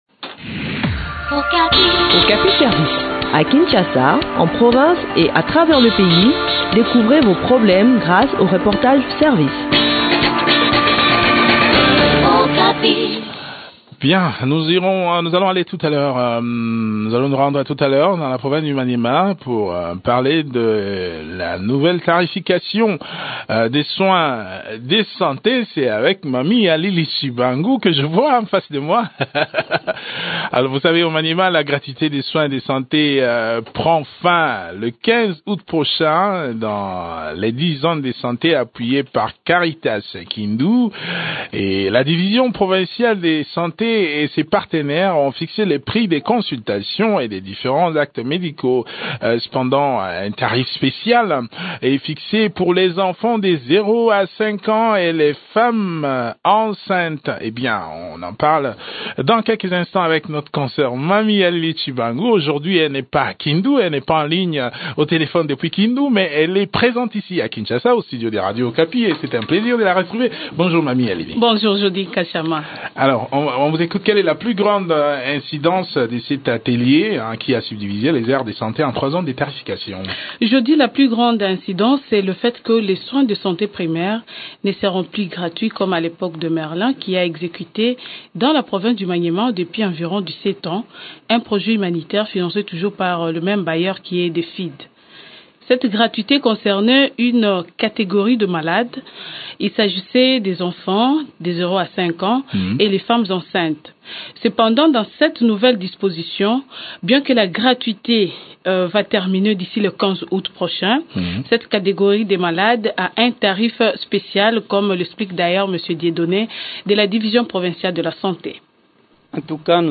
Le point sur l’évolution de ce projet sur le terrain dans cet entretien